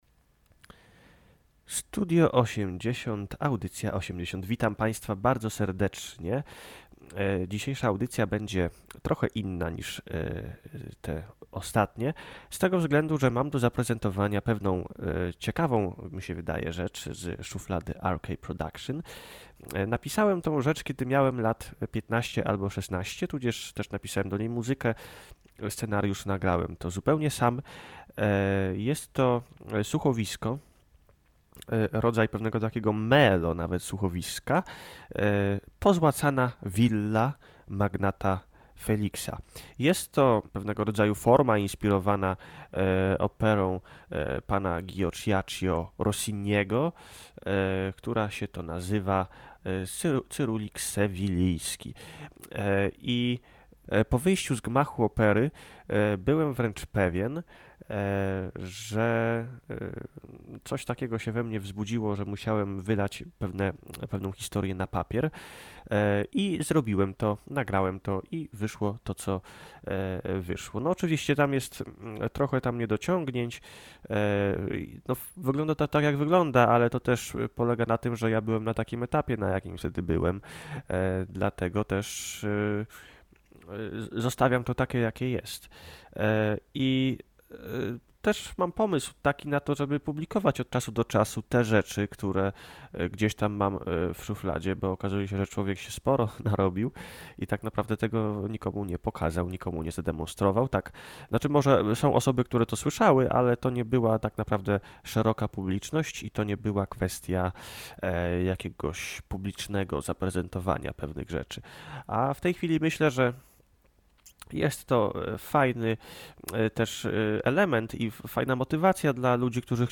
Dzisiaj prezentujemy słuchowisko nagrane przez R.K Production lata temu. Tematem przewodnim tej produkcji jest poszukiwanie odpowiedzi na pytanie: Czy impulsywny choleryk i tyran może być naiwnym idiotą?